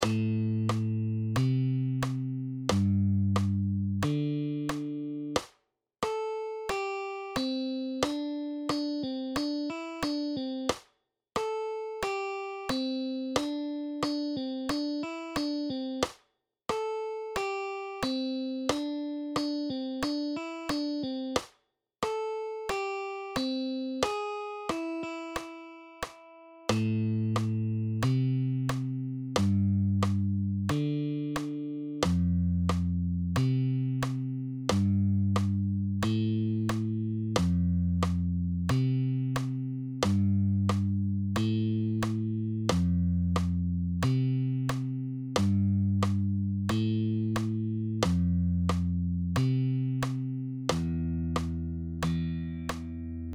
Kenties nopein tapa päästä soittamaan musiikkia on soittaa kappaleen sointujen perussäveliä, joita kutsutaan myös pohjasäveliksi.
Tässä tapauksessa monet sävelet saadaan soitettua vapailta kieliltä, jotka on merkitty nolliksi. Jokainen sävel on puolinuotin mittainen, eli se soi kahden iskun ajan.
pohjasävelet.mp3